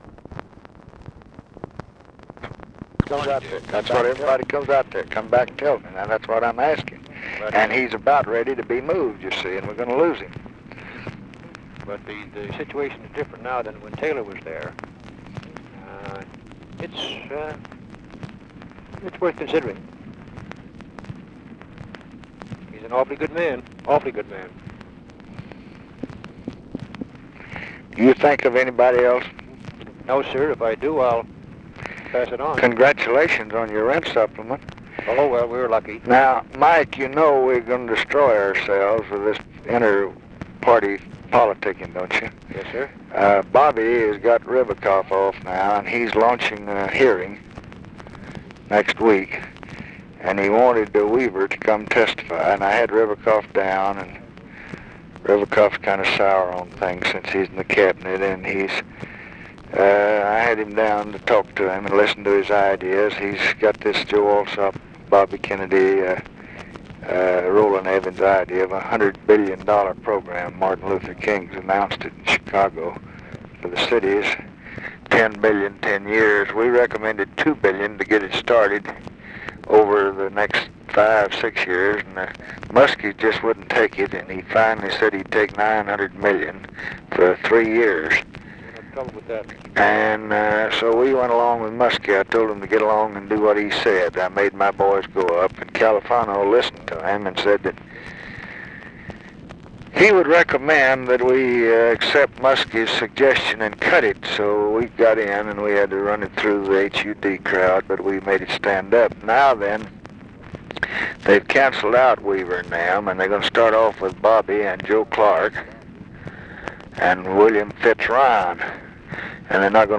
Conversation with MIKE MANSFIELD, August 10, 1966
Secret White House Tapes